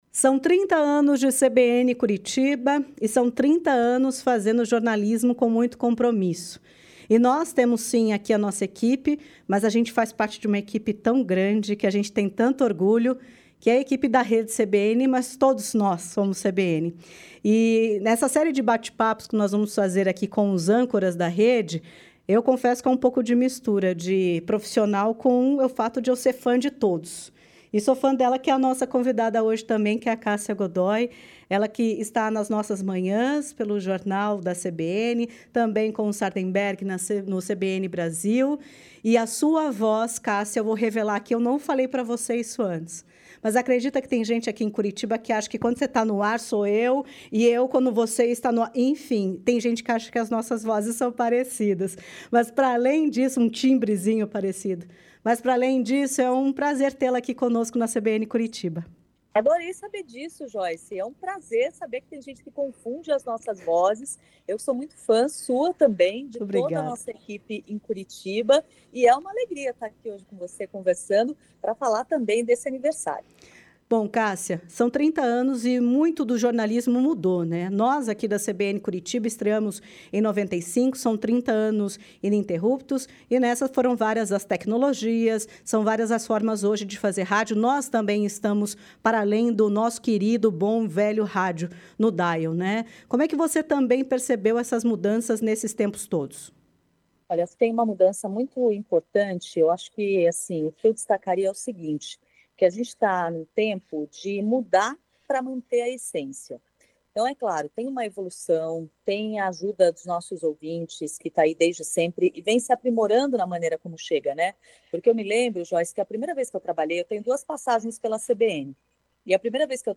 A primeira conversa